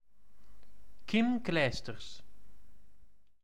Kim Antonie Lode Clijsters[3] (Dutch pronunciation: [kɪm ˈklɛistərs]
Nl-be_kim_clijsters.ogg.mp3